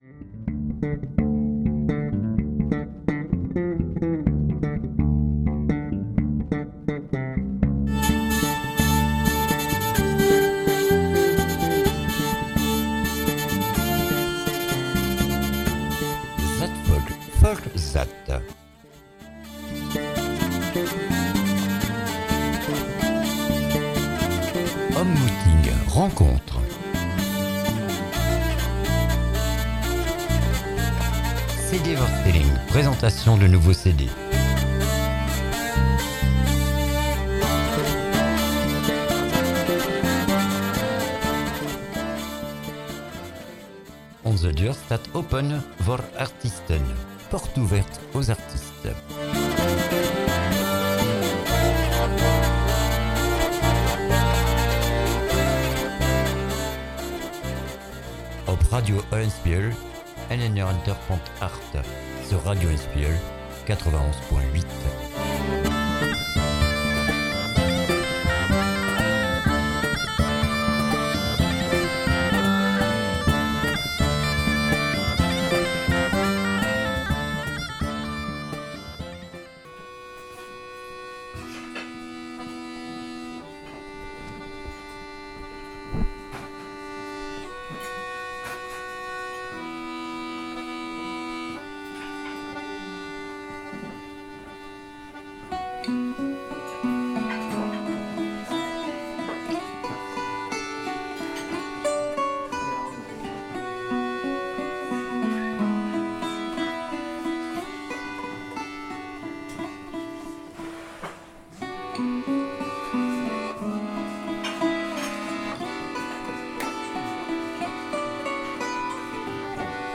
CONCERT